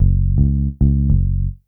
bass_06.wav